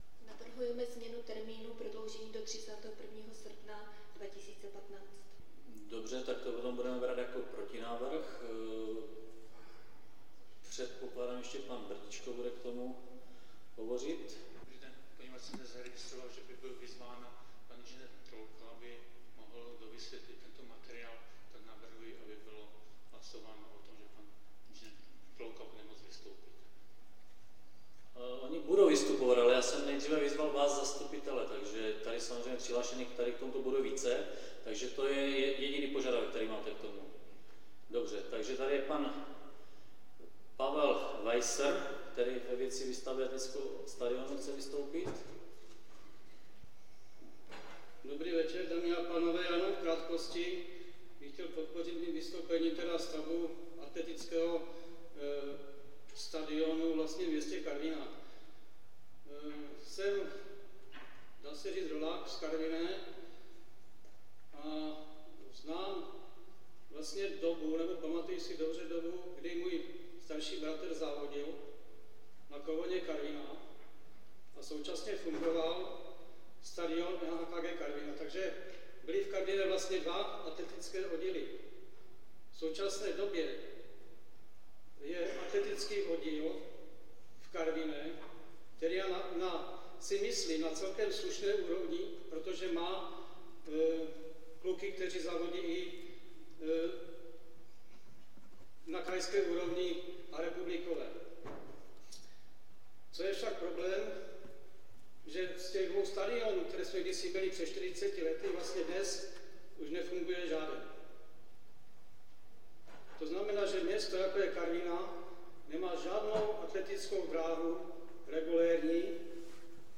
Celá věc se řešila přes hodinu na čtvrtečním zasedání Zastupitelstva města